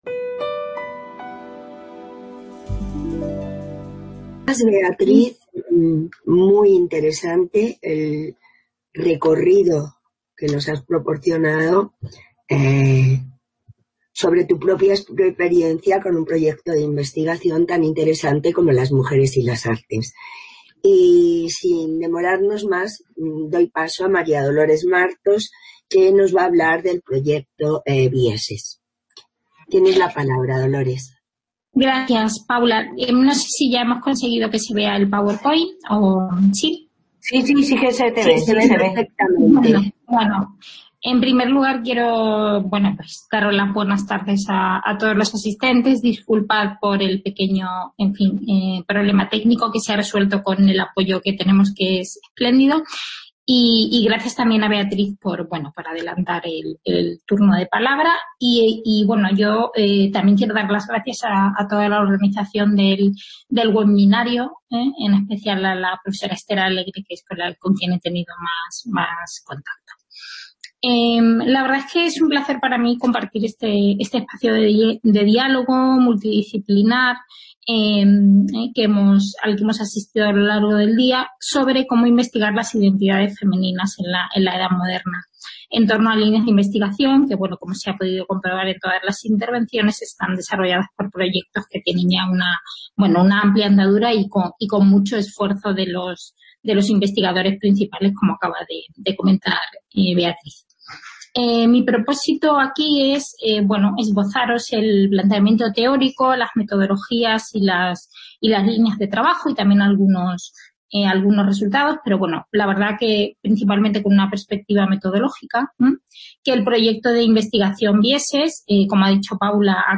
En este Seminario Web, queremos conocer y reflexionar sobre experiencias concretas de investigación que han optado por la exploración de amplios colectivos de mujeres de la Edad Moderna.